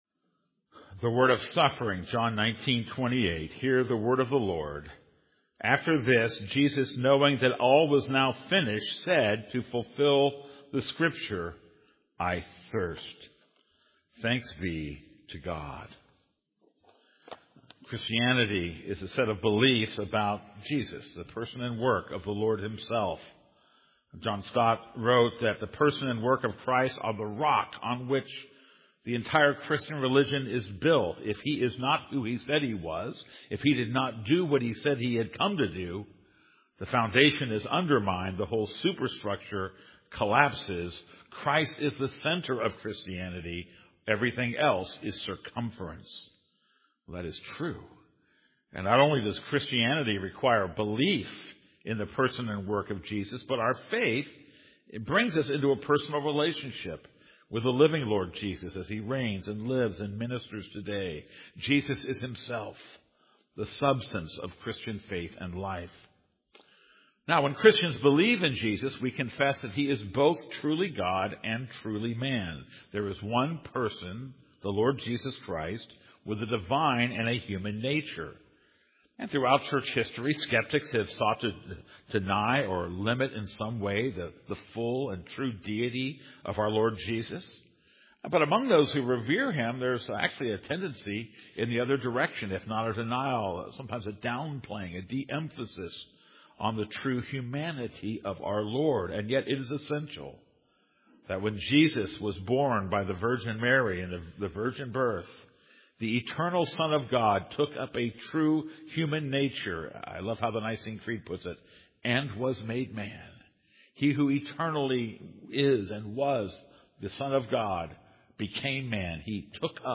This is a sermon on John 19:28.